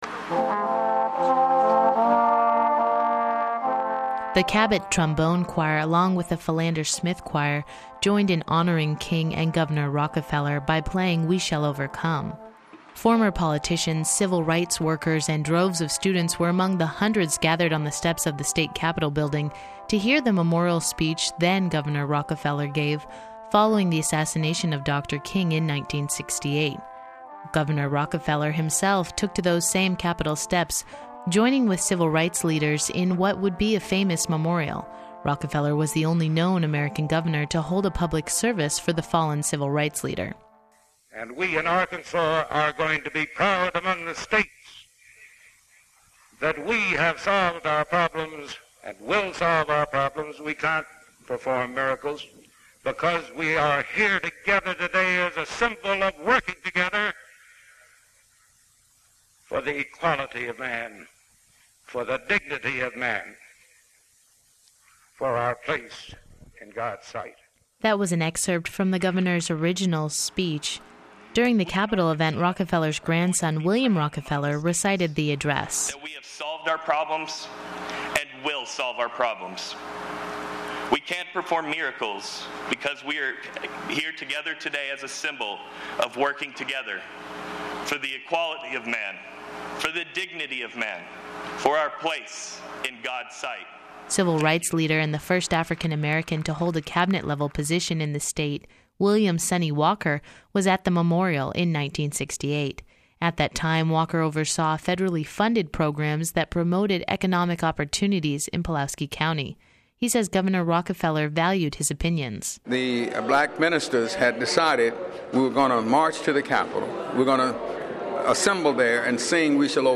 RockefellerMLKTribute.mp3